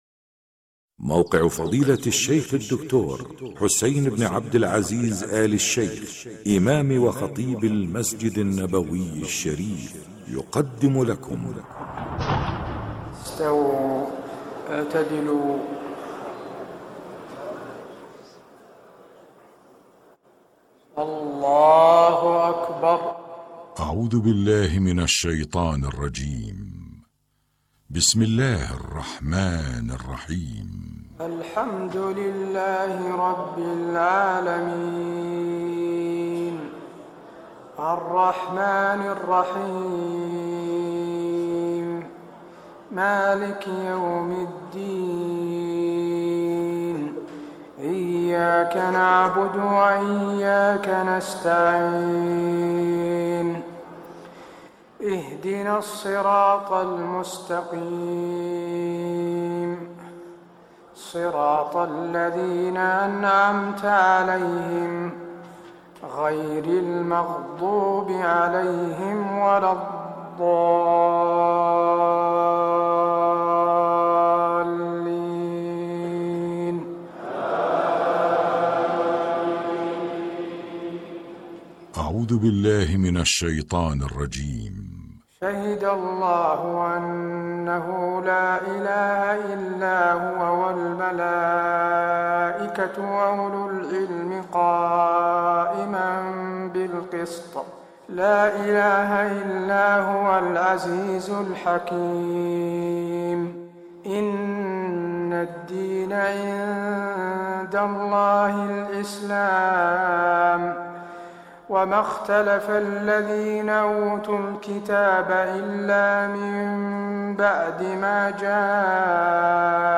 تهجد ليلة 23 رمضان 1425هـ من سورة آل عمران (18-51) Tahajjud 23 st night Ramadan 1425H from Surah Aal-i-Imraan > تراويح الحرم النبوي عام 1425 🕌 > التراويح - تلاوات الحرمين